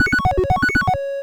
retro_beeps_success_02.wav